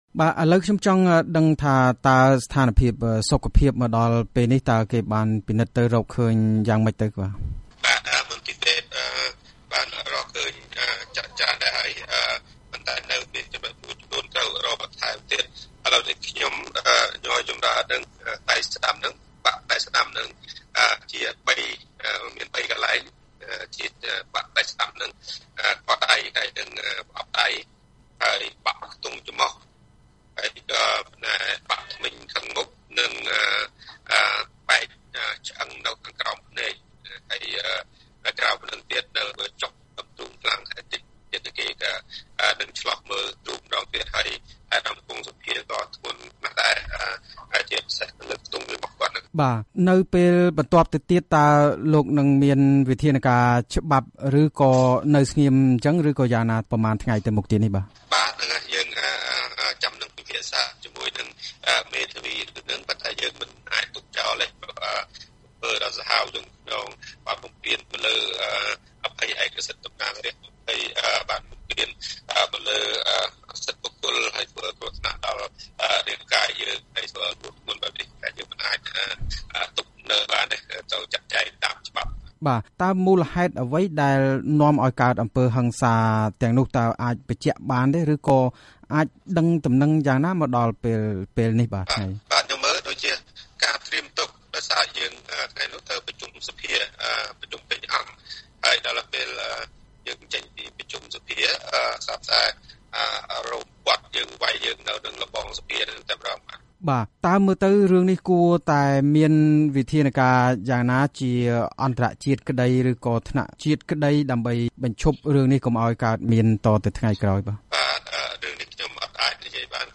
អ្នកតំណាងរាស្ត្រគណបក្សសង្គ្រោះជាតិគឺលោកគង់ សភានិងលោកញ៉យ ចំរើន  ដែលរងការវាយទាត់ធាក់ពីមនុស្សមួយក្រុមកាលពីរសៀលថ្ងៃច័ន្ទនោះកំពុងសម្រាកព្យាបាលរបួសនៅរាជធានីបាងកក ប្រទេសថៃ។ លោកទាំងពីរបានចេញដំណើរទៅព្យាបាលនៅមន្ទីរពេទ្យ Phyathai កាលពីព្រឹកថ្ងៃអង្គារនេះ។ ពេលនេះ លោកទាំងពីរនិយាយថា លោកមានរបួសបាក់ខ្ទង់ចម្រុះ របួសក្បាល បាក់ជើង ឈឺទ្រូង ដែលនាំឲ្យលោកនិយាយមិនបានច្បាស់។ លោកទាំងពីរបានអះអាងថា រឿងអំពើហិង្សាកើតឡើងនេះទំនងជារឿងនយោបាយ ហើយលោកទាំងពីរបានអំពាវនាវឲ្យយុវជនស្នេហាជាតិមានភាពក្លាហាន តស៊ូឲ្យមានការ ផ្លាស់ប្តូរសង្គមកម្ពុជានិងអំពាវនាវឲ្យអ្នកនយោបាយយកប្រាជ្ញាដោះស្រាយបញ្ហាប្រទេសជាជាងប្រើអំពើហិង្សា។ សូមស្តាប់កិច្ចសម្ភាសន៍នេះដូចតទៅ៖
បទសម្ភាសន៍ជាមួយ លោក ញ៉យ ចំរើន តំណាងរាស្ត្រគណបក្សសង្គ្រោះជាតិដែលរងរបួស